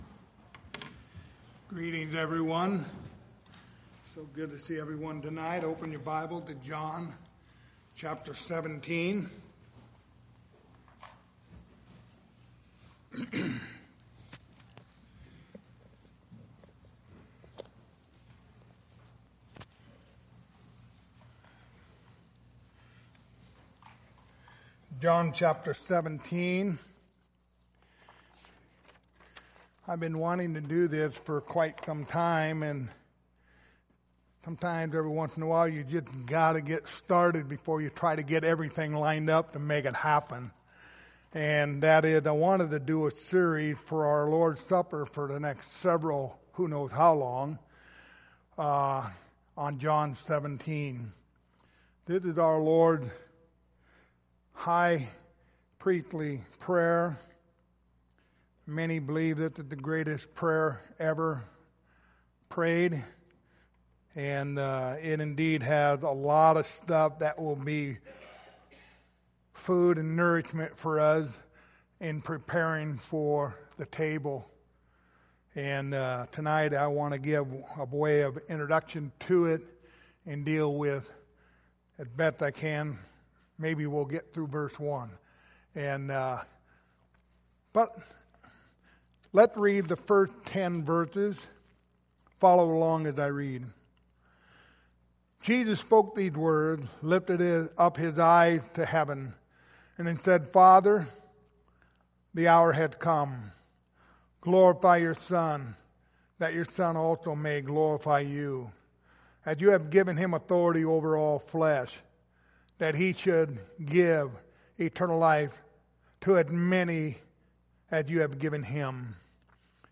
Lord's Supper Passage: John 17:1-10 Service Type: Lord's Supper Topics